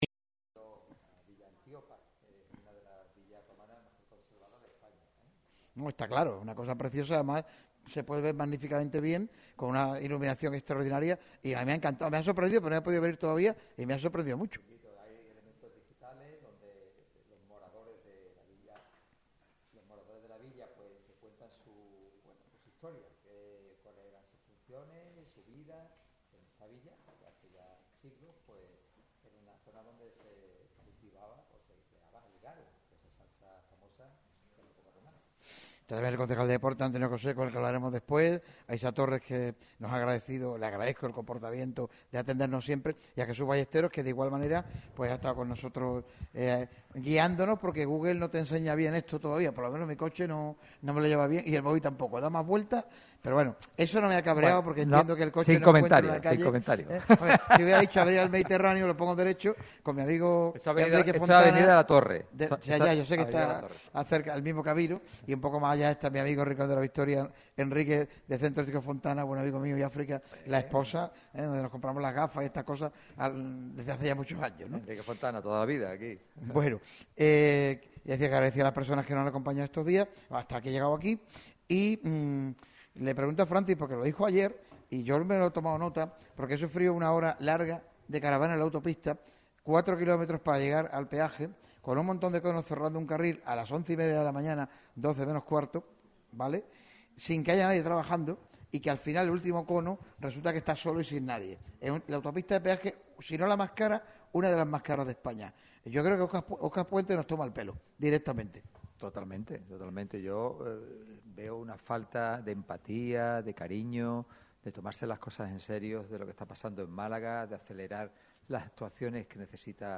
La radio del deporte ha llevado a cabo un programa especial en el museo arqueológico de Villa Antiopa.